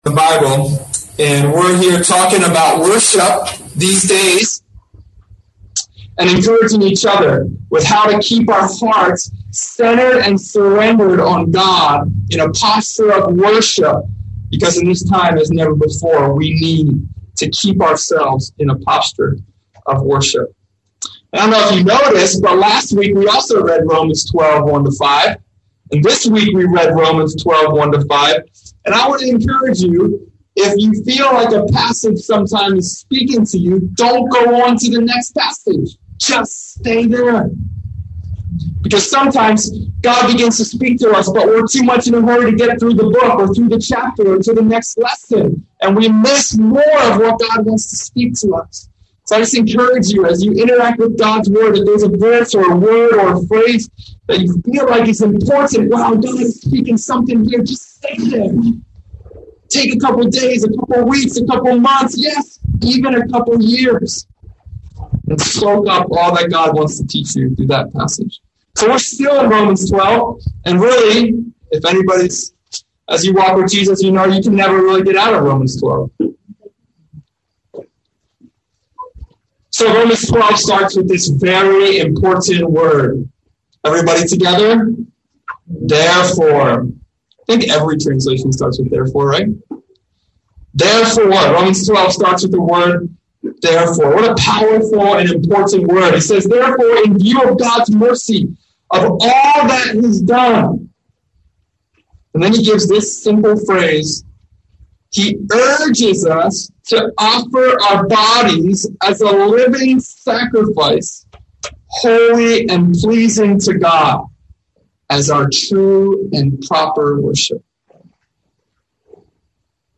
(We apologize for the low sound quality as we are holding outdoor services.).